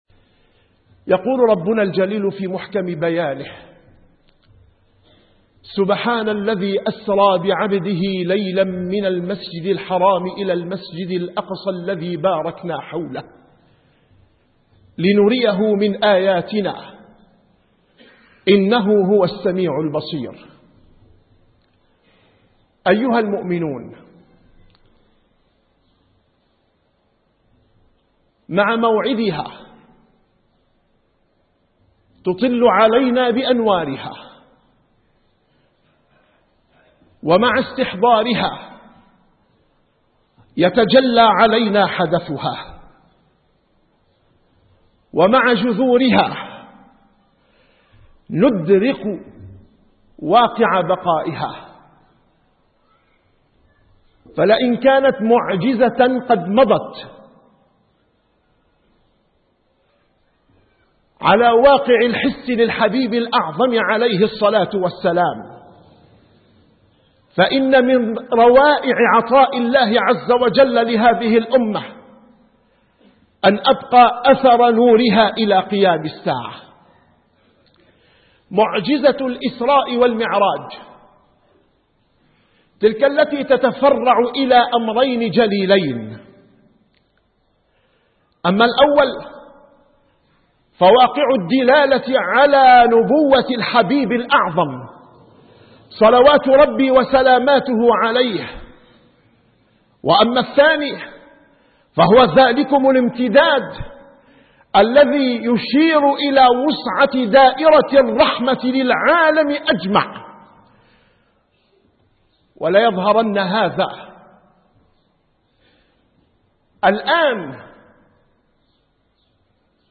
- الخطب